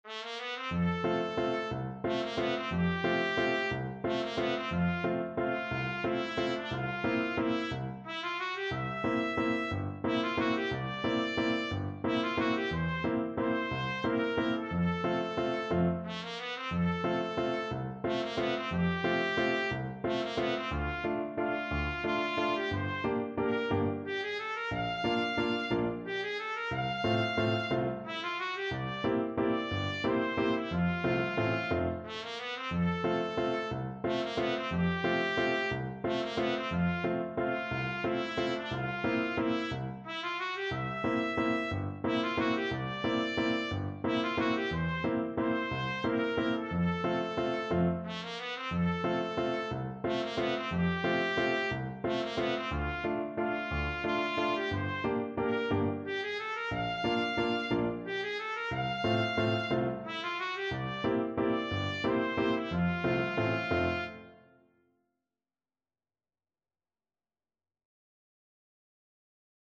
3/4 (View more 3/4 Music)
One in a bar .=c.60
A4-F6